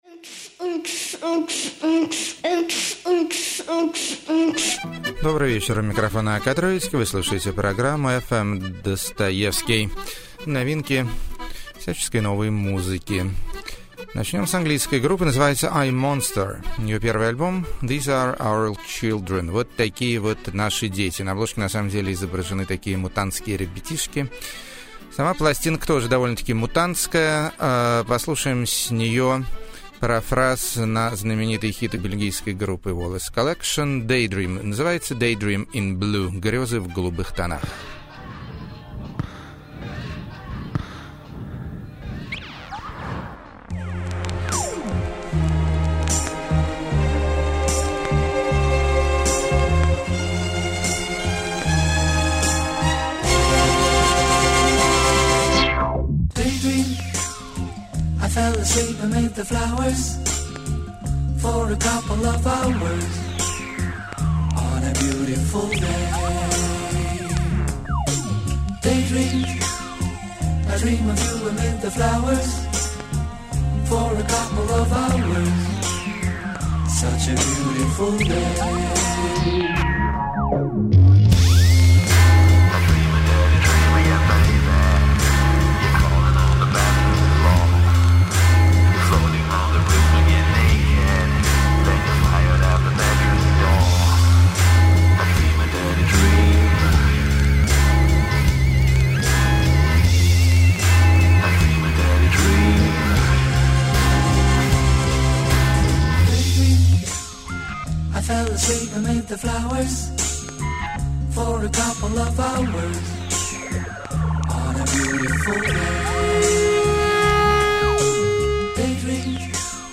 Интеллектуальные Электро-песенки.
Сюрной Вокальный Пост-рок.
Зажигательные Трансильванские Напевы.
Сентиментальная Электроника.